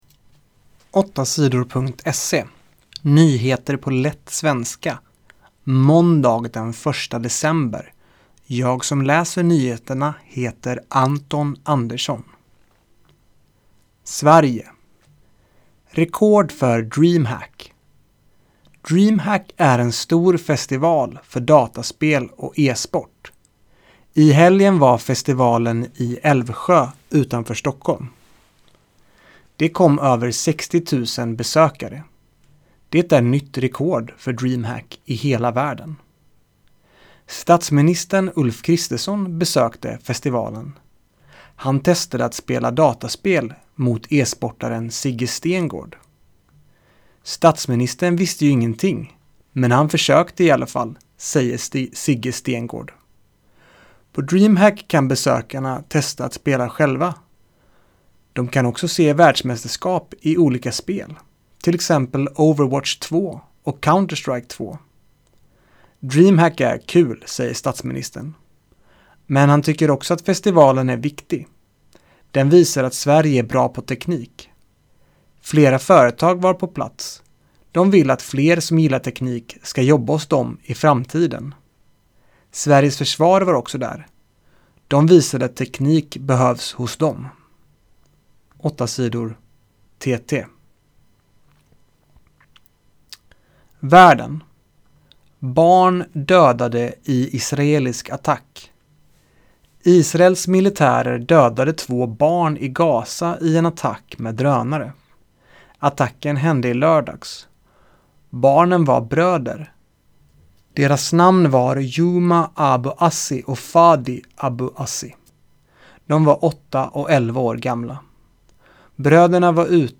Nyheter på lätt svenska den 1 december